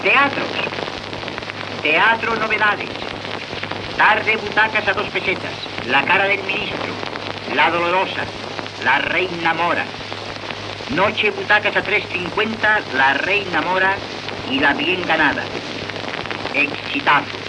Presentador/a